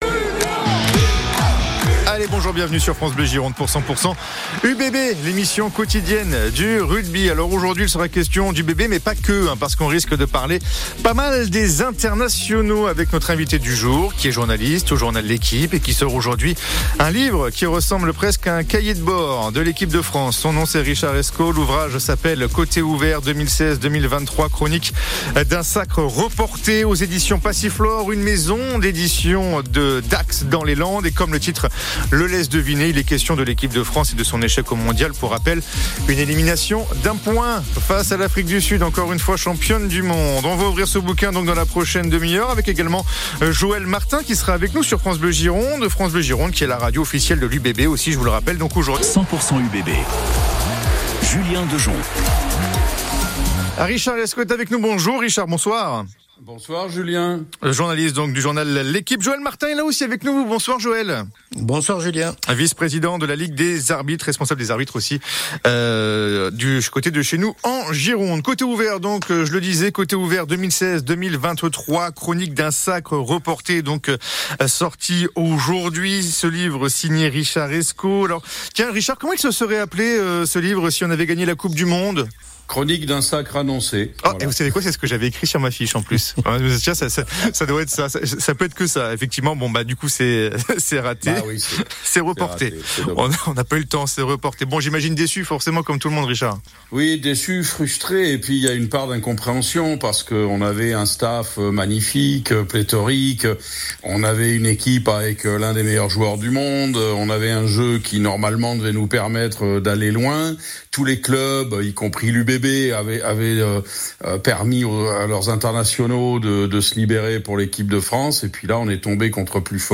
L'émission radio de France Bleu Gironde qui parle de la sortie du livre